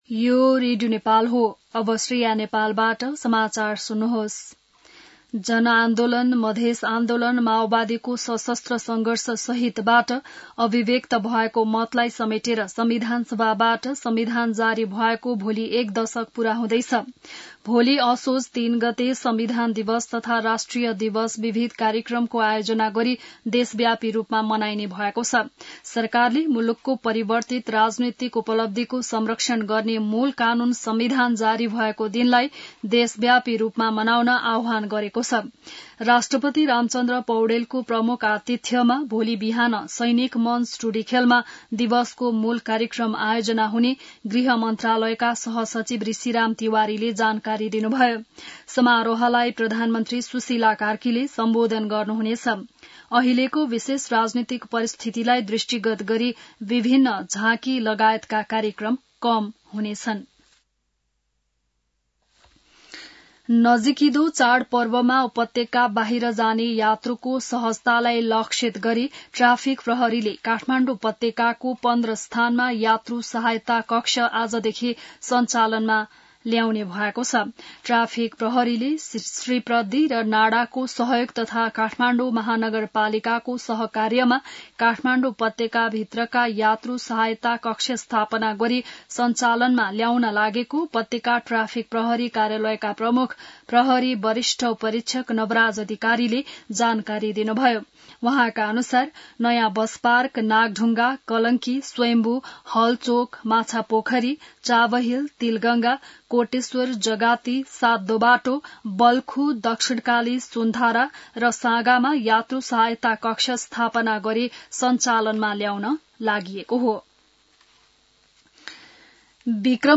An online outlet of Nepal's national radio broadcaster
बिहान १० बजेको नेपाली समाचार : २ असोज , २०८२